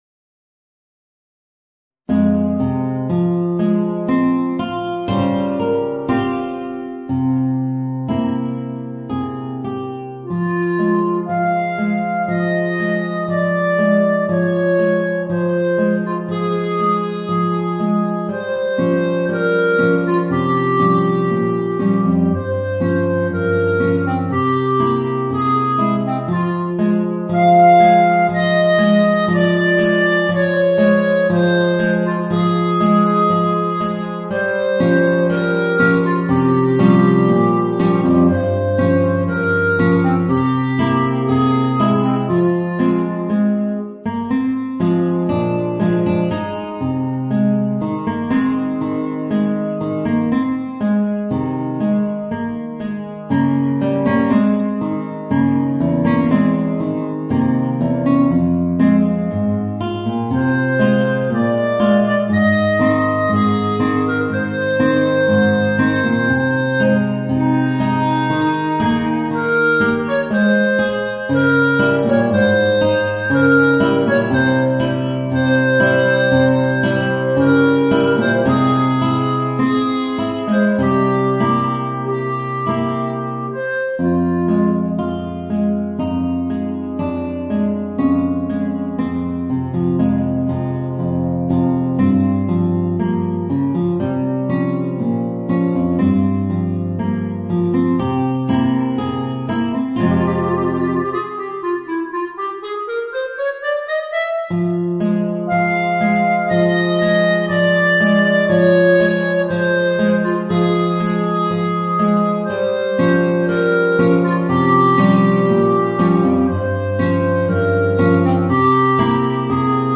Voicing: Clarinet and Guitar